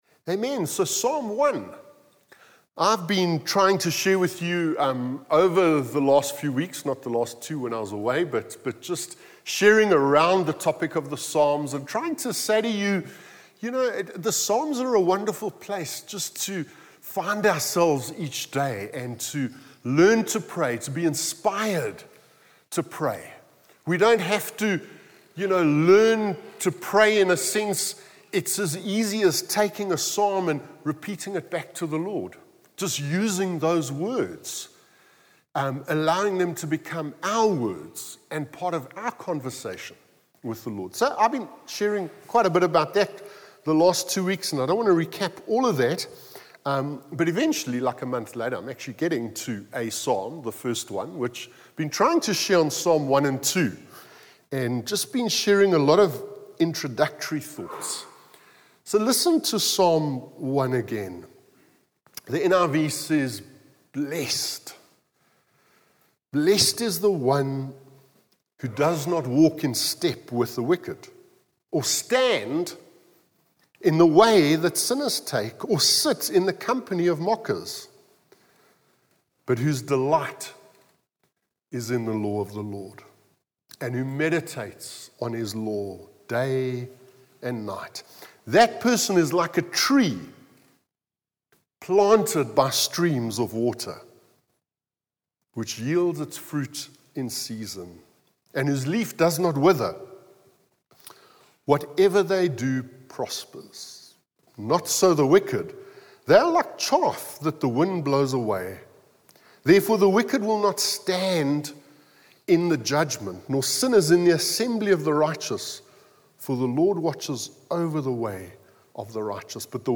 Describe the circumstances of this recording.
From Hillside Vineyard Christian Fellowship, at Aan-Die-Berg Gemeente.